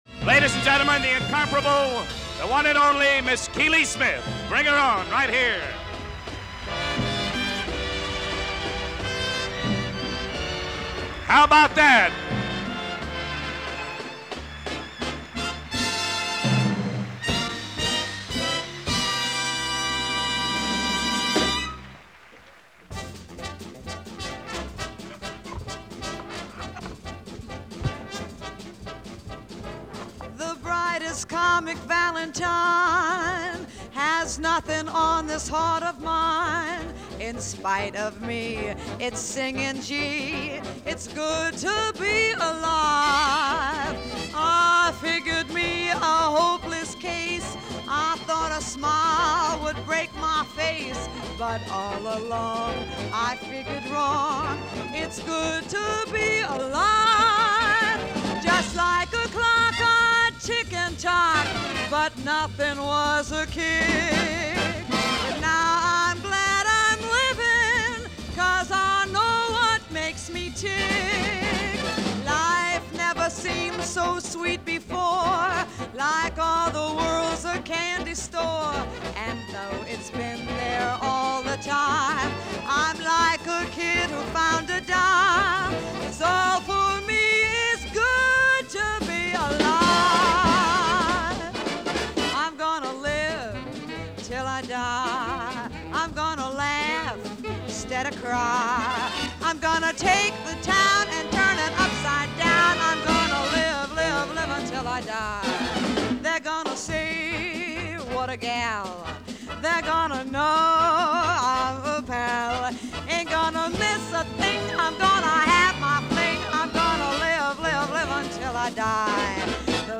It was The Hollywood Bowl and she was headliner.